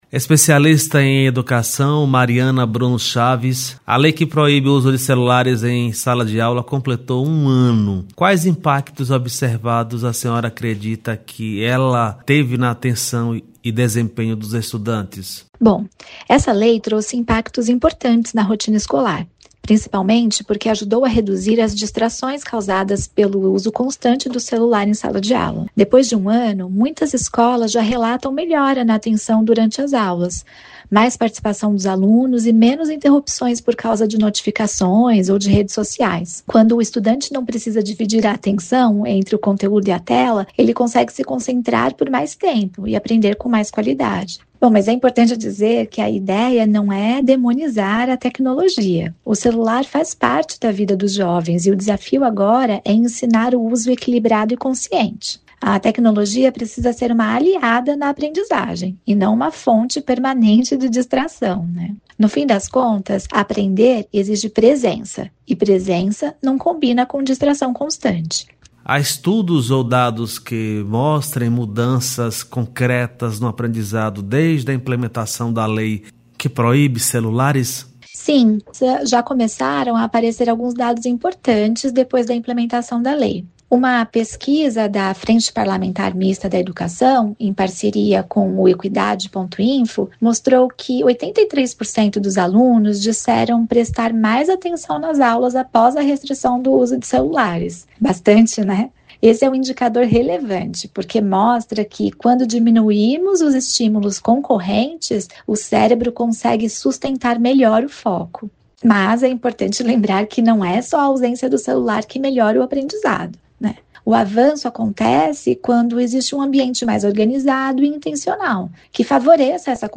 Um ano da lei que proíbe celular nas escolas: Especialista fala sobre o assunto.